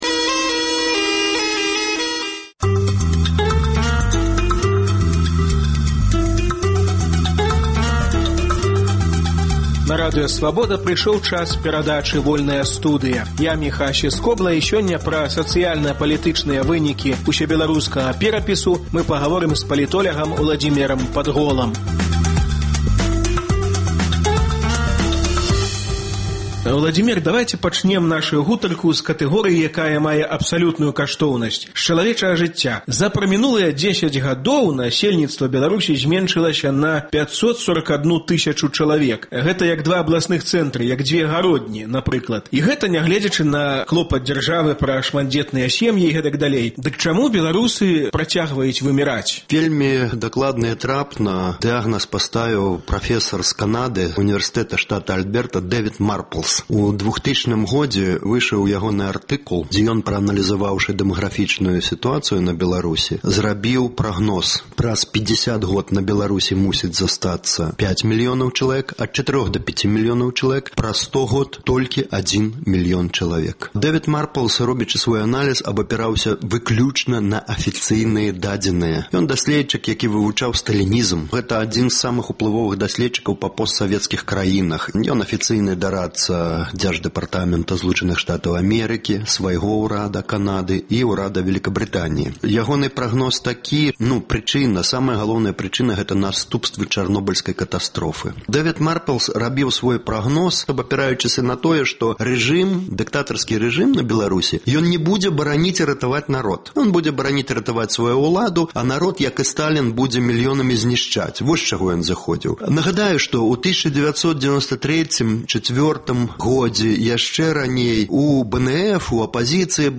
За прамінулыя дзесяць гадоў з мапы Беларусі непрыкметна зьніклі два такіх гарады як Горадня – на 541 000 чалавек паменшала насельніцтва. Што можа ўратаваць беларускі народ ад выміраньня і дэнацыяналізацыі? Гутарка